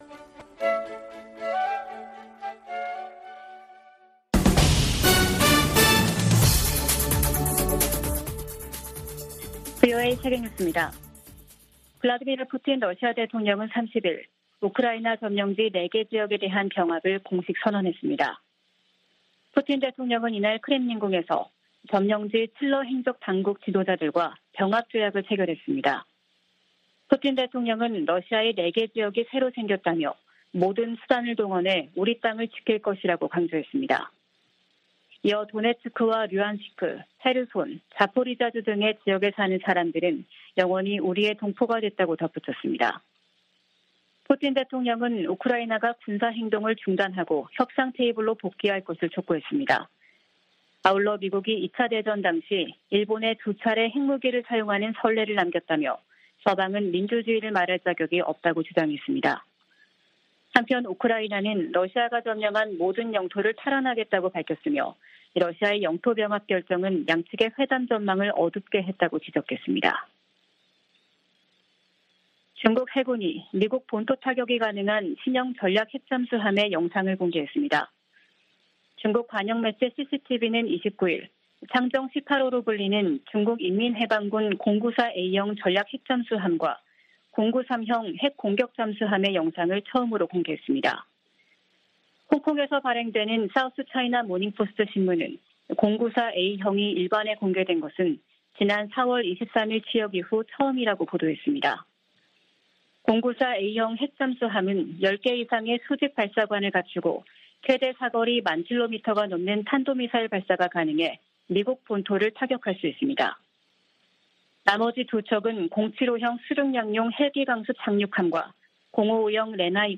VOA 한국어 아침 뉴스 프로그램 '워싱턴 뉴스 광장' 2022년 10월 1일 방송입니다. 백악관은 카멀라 해리스 부통령이 한국에서 미국의 확장억제 의지를 재확인했다고 밝혔습니다. 북한이 닷새 간 세 차례 탄도미사일 도발을 이어가자 한국 정부는 국제사회와 추가 제재를 검토하겠다고 밝혔습니다. 미한일이 5년만에 연합 대잠수함 훈련을 실시하고 역내 도전에 공동 대응하기로 했습니다.